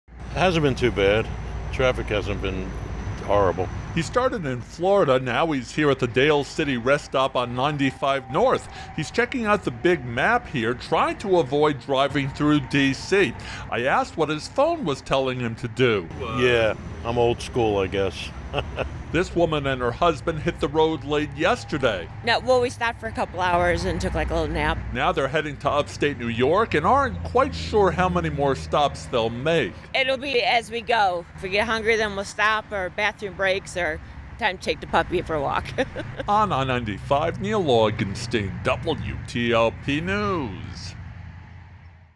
talks to travelers who are trying to beat potential heavy traffic ahead of long Labor Day weekend